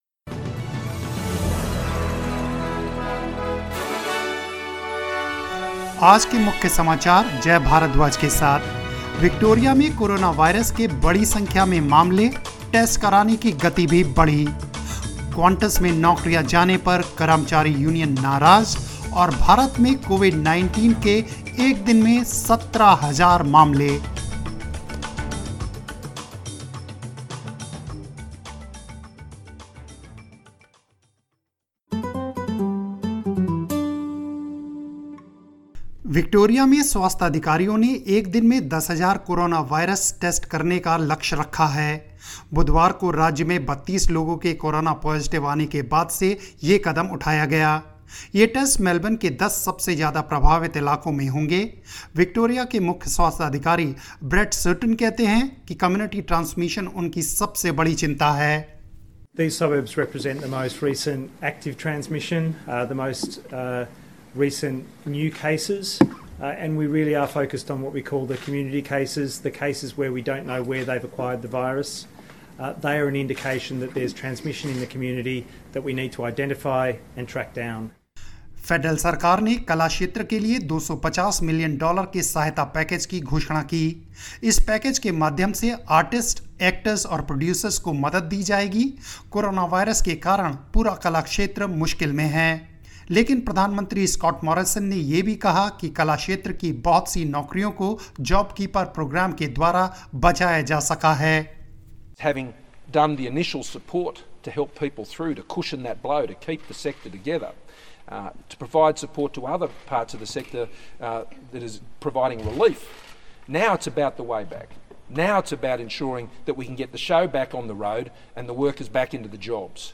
News in Hindi 25 June 2020